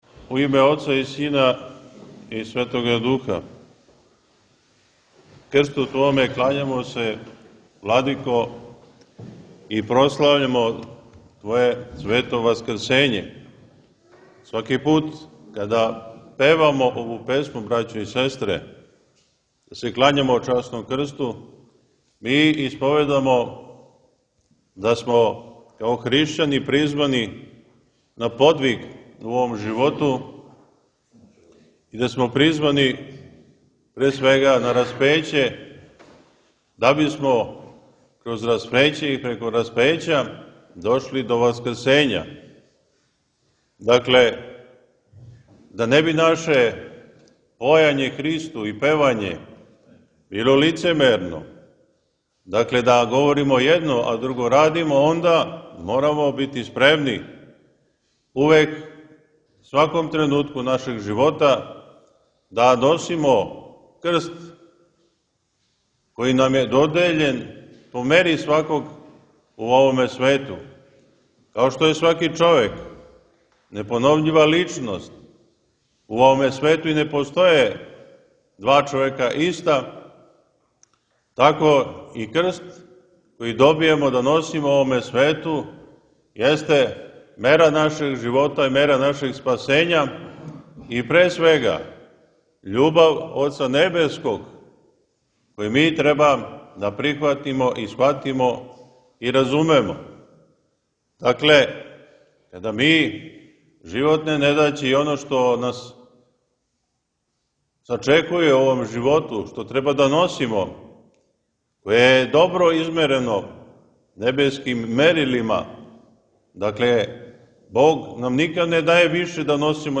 Liturgijska besjeda